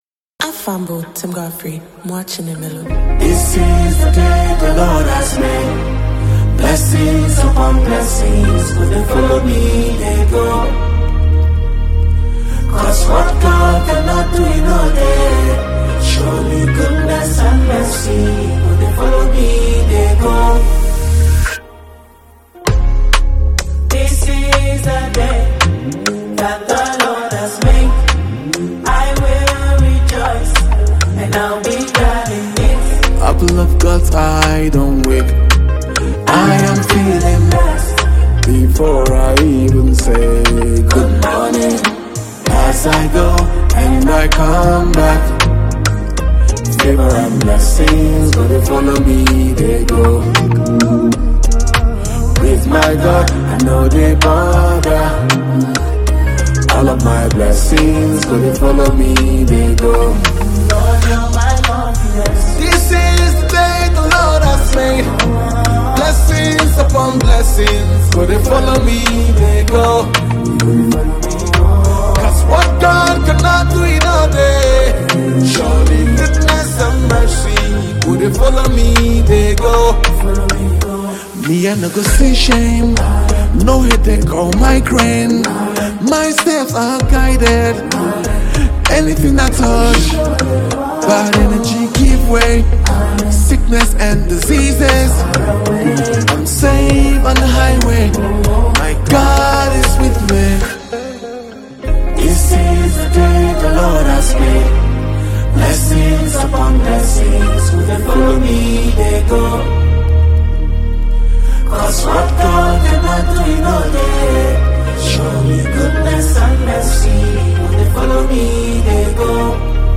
high-energy declaration
infectious Afro-Gospel rhythm
• Genre: Gospel / Contemporary Praise